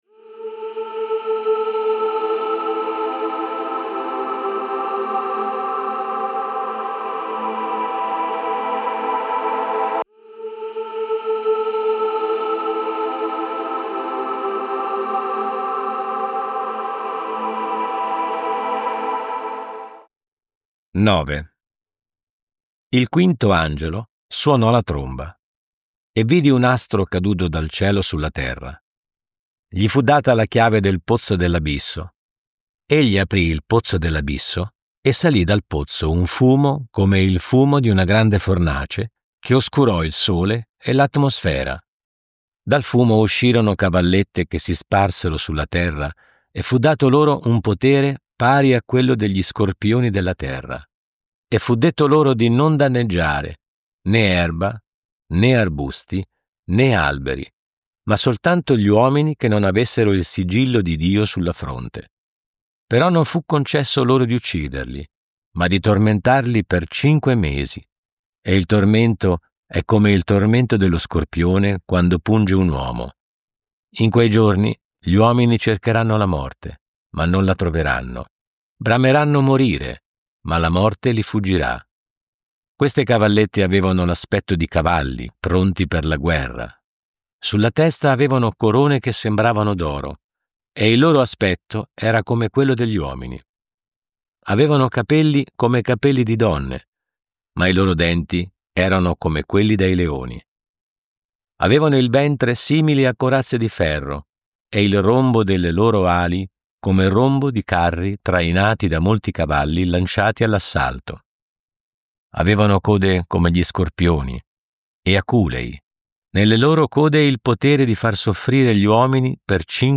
Audiolibri integrali, sempre gratis.